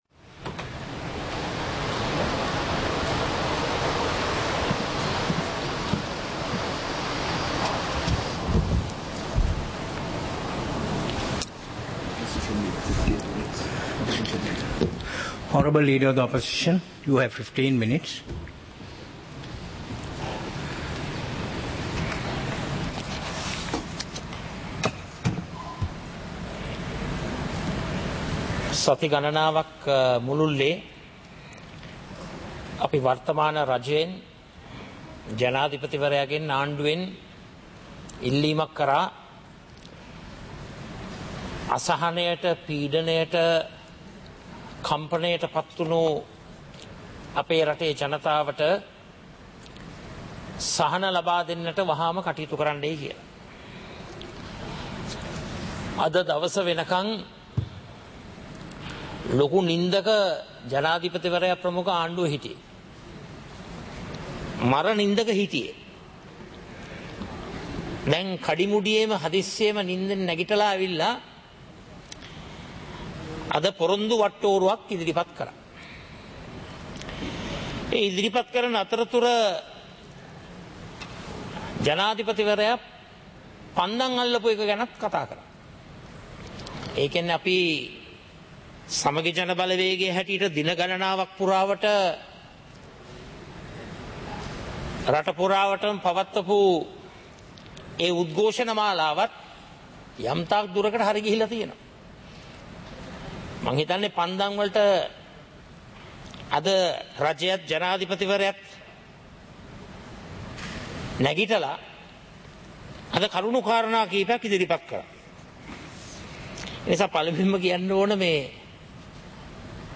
சபை நடவடிக்கைமுறை (2026-04-07)
நேரலை - பதிவுருத்தப்பட்ட